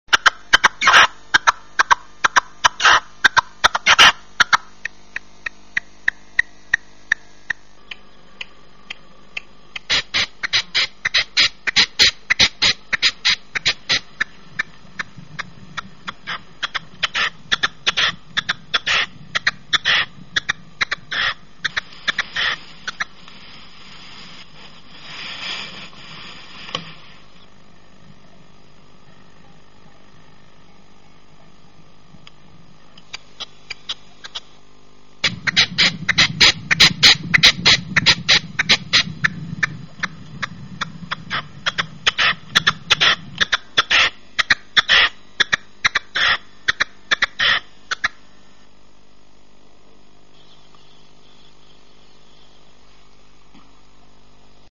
Perdiz-comum
Alectoris rufa
Local: Terreno inculto, Vestiaria, 27 de Dezembro de 2015
Perdiz.mp3